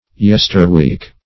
Yesterweek \Yes"ter*week`\, n. The week last past; last week.